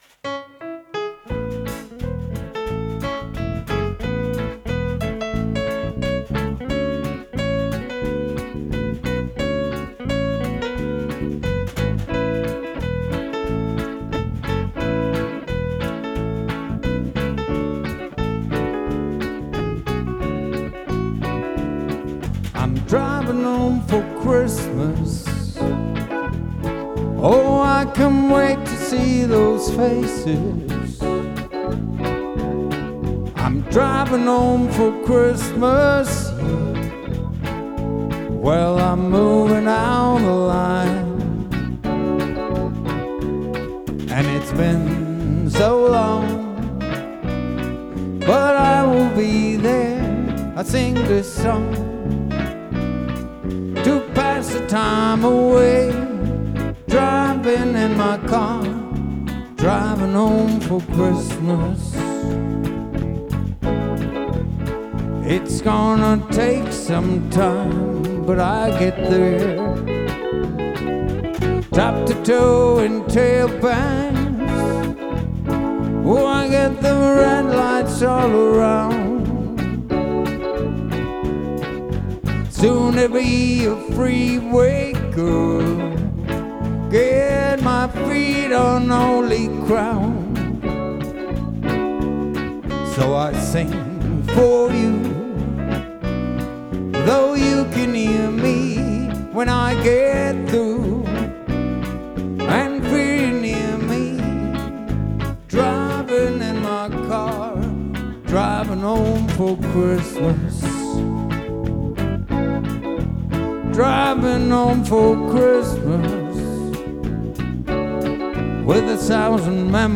Dabei kam mein Yamaha BB234 zum Einsatz.